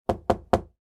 Download Knock sound effect for free.
Knock